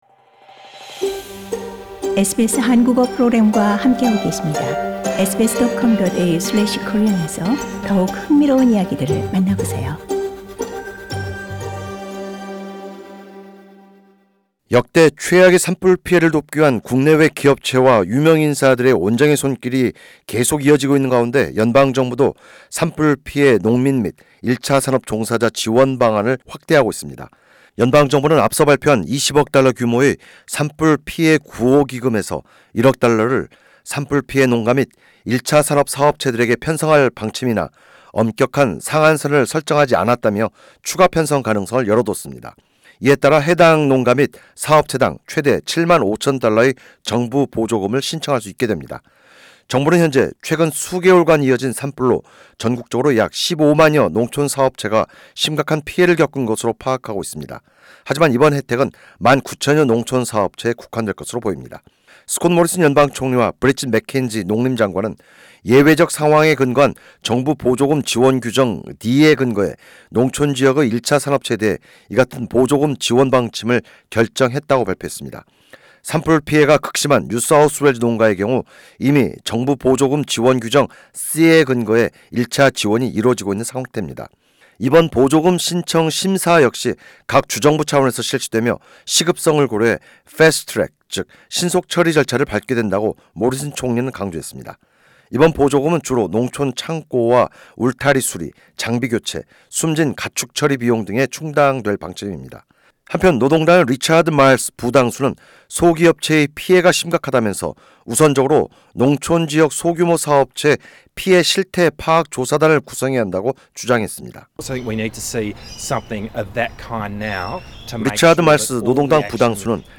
[상단의 팟캐스트를 통해 오디오 뉴스가 제공됩니다.]